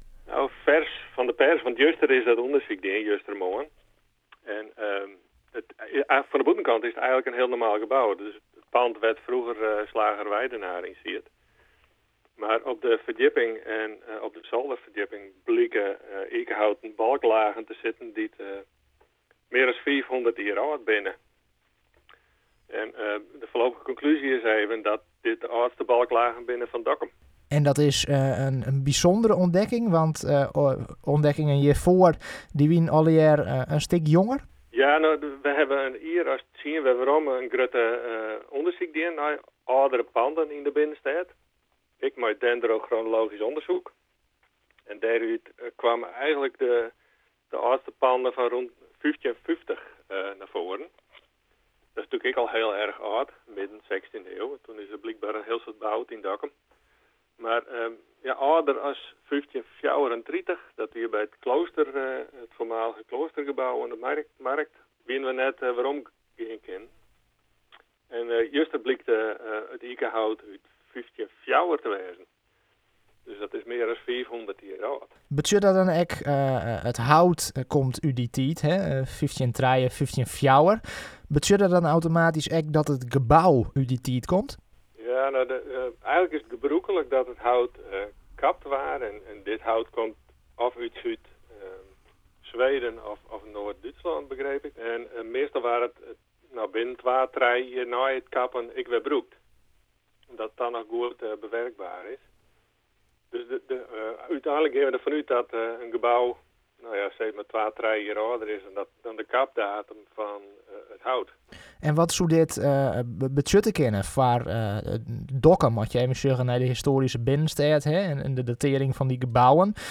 Bouwhistoricus